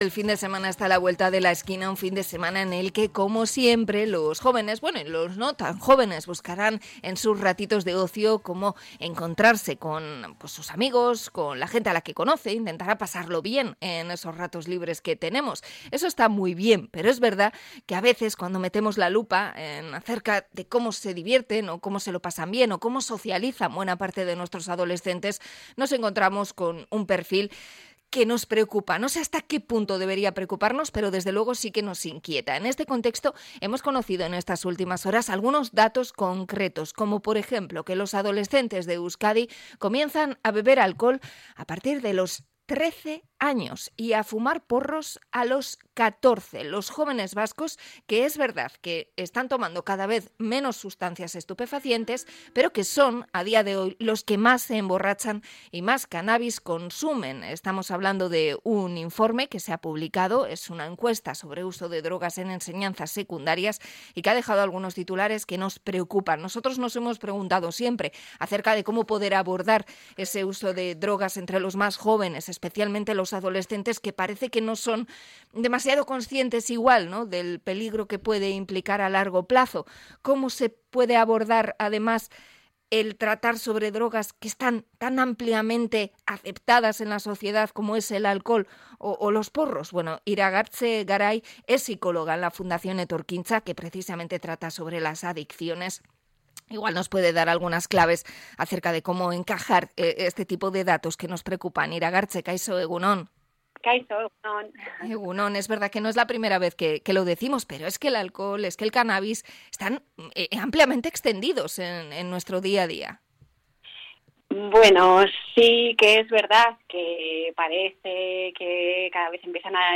Entrevista a la Fundación Etorkintza por el consumo de sustancias en los jóvenes